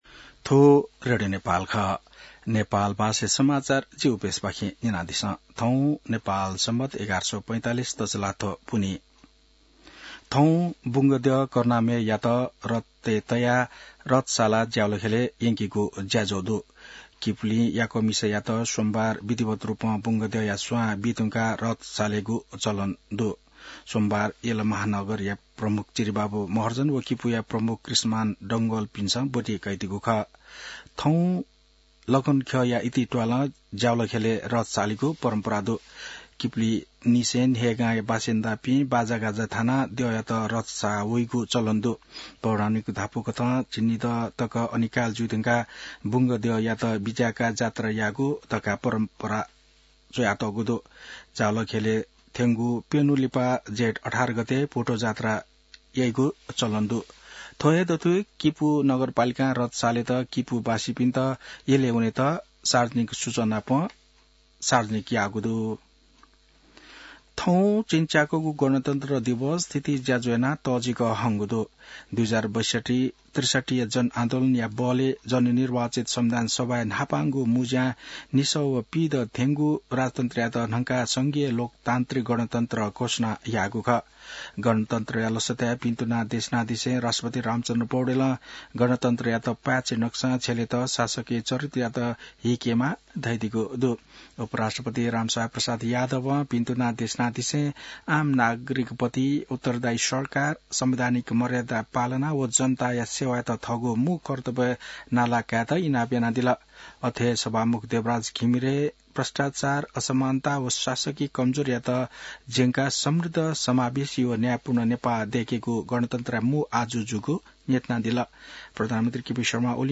नेपाल भाषामा समाचार : १५ जेठ , २०८२